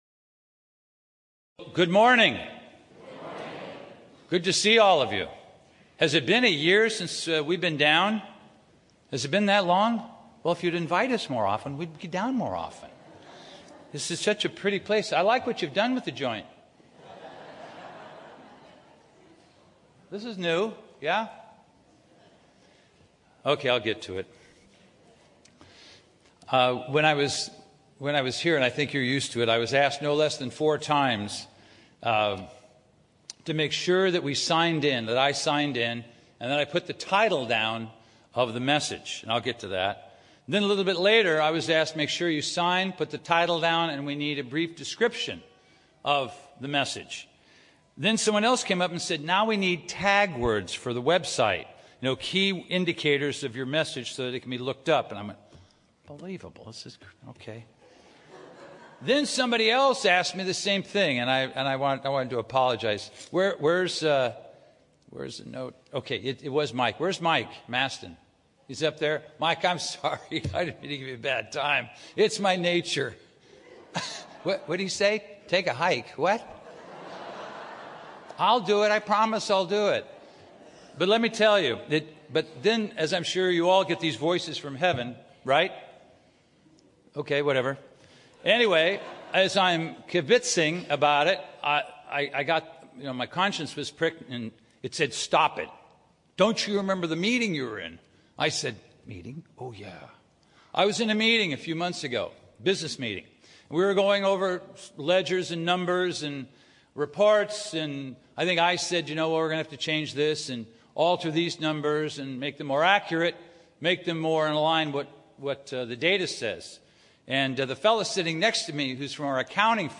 Included in the meaning of the Feast of Trumpets is the sounding of the last trumpet and the resurrection of the righteous to eternal life in God's family. This sermon reflects on that holy day and is a message about God's hands and the great joy of knowing that He will write our name in the Book of Life if we respond to His calling and endure to the end.